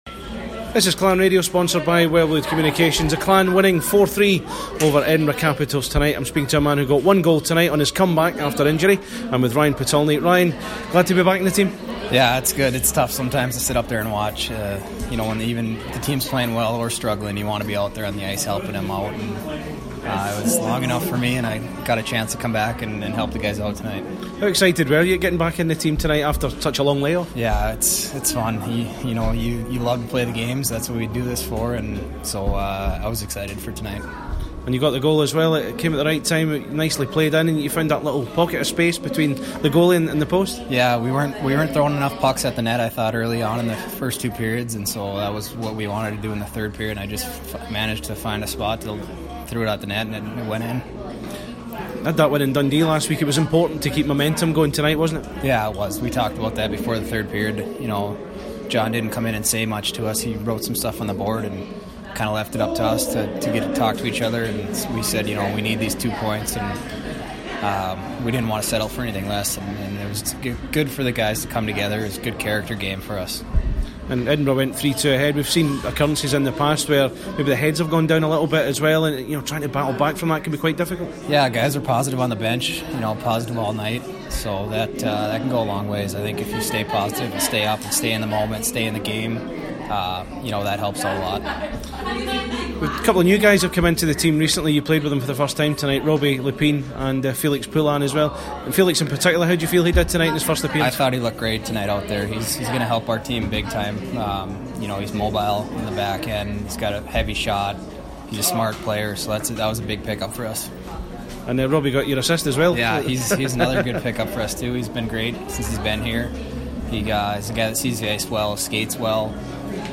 He spoke to Clan Radio post game